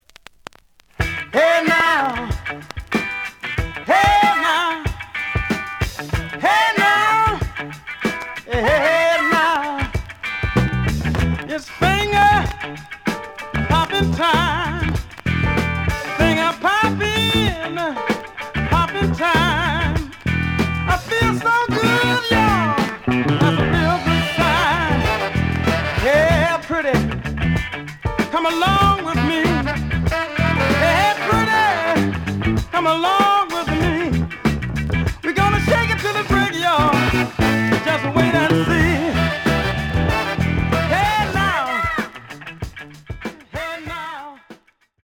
The audio sample is recorded from the actual item.
●Genre: Funk, 70's Funk
Some click noise on beginning of B side due to scratches.)